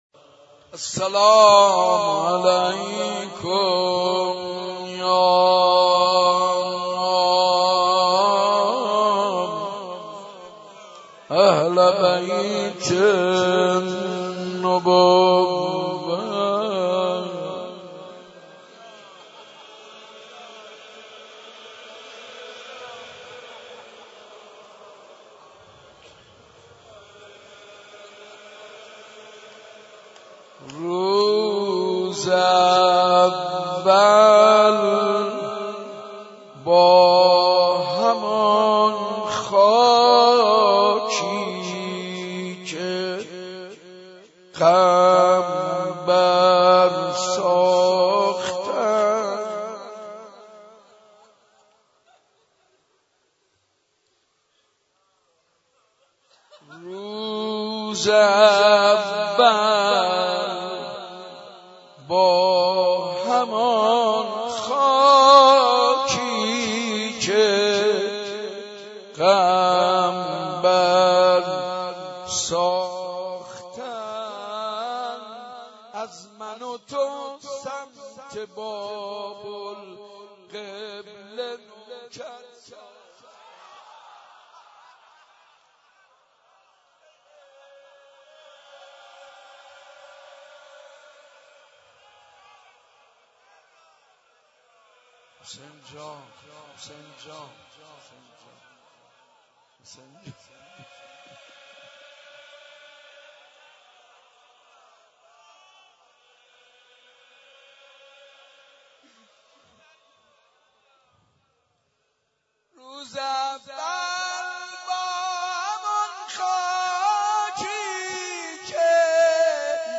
حاج منصور ارضی/شب دوم محرم95/مسجد ارک